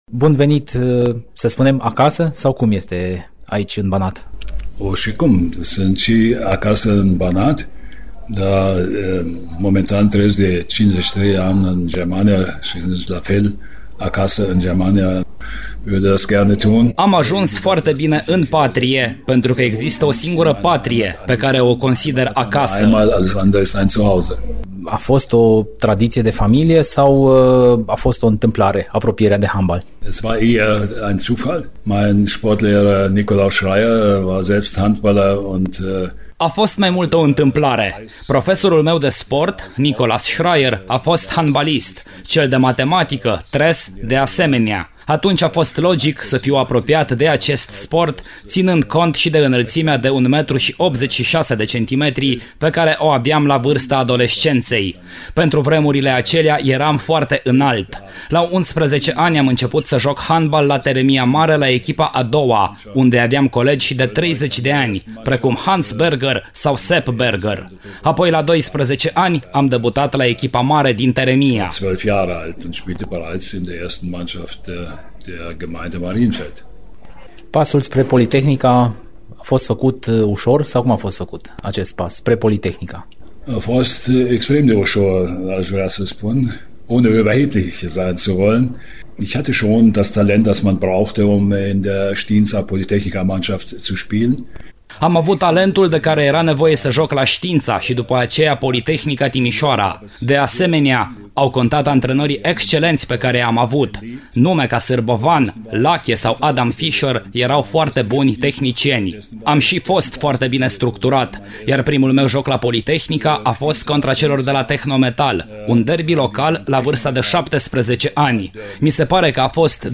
Divizia Sport continuă seria de interviuri cu mari nume ale sportului de altădată, iar astăzi, la „Arena Radio”, a fost invitat Hansi Schmidt.